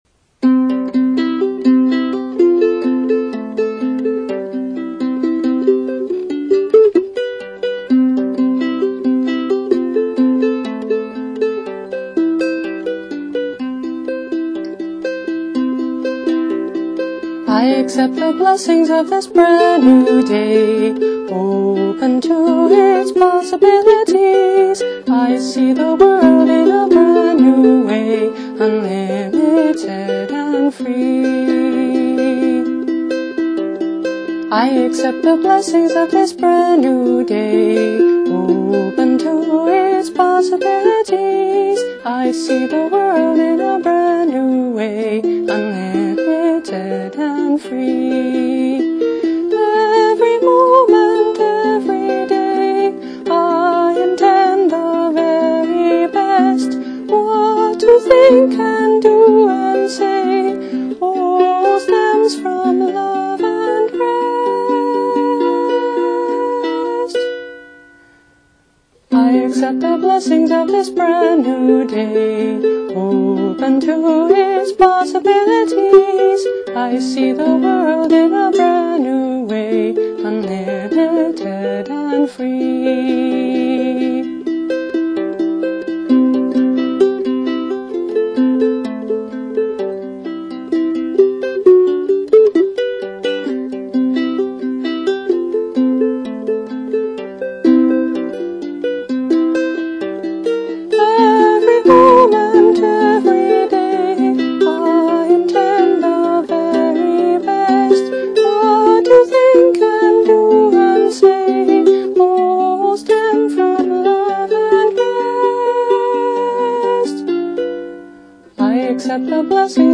Instrument: Lady – tenor Flea ukulele